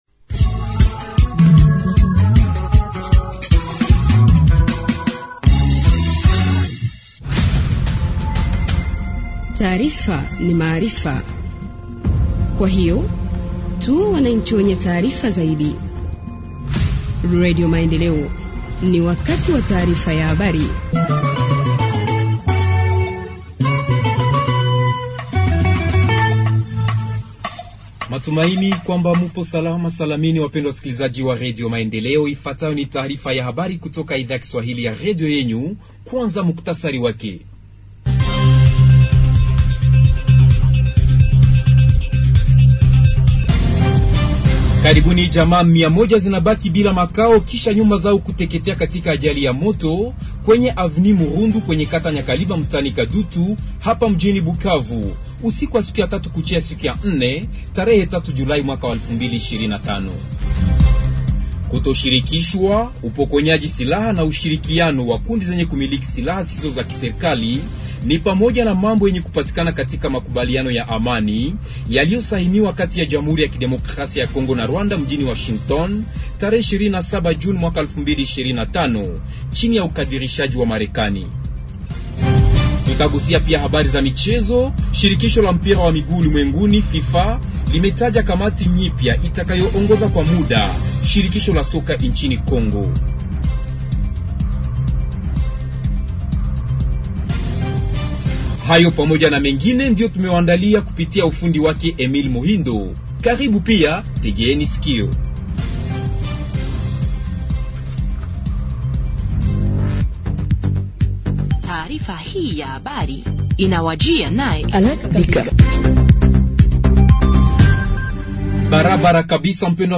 Journal en Swahili du 04 juillet 2025 – Radio Maendeleo